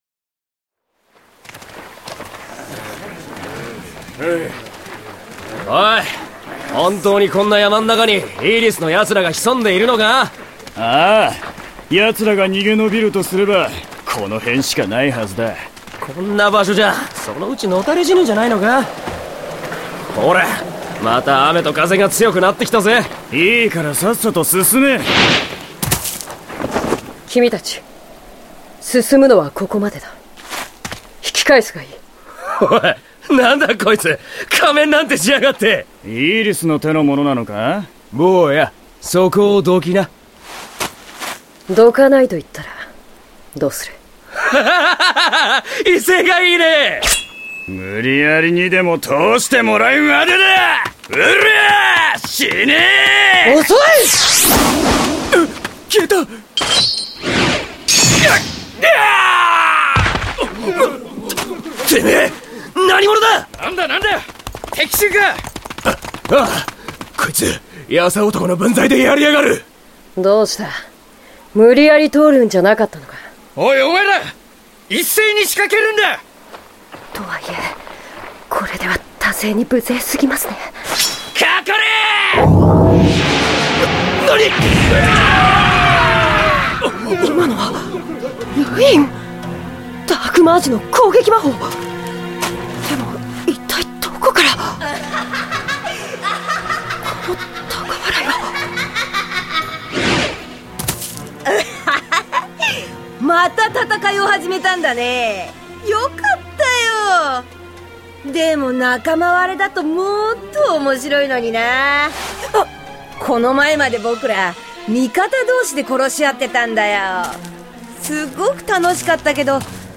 He's intended to sound youthful, loud, grating, and jarring, especially when you imagine him using this sing-song voice while talking about gruesome matters.
Here is a longer collection of clips of JP!Henry talking, starting at 1:35.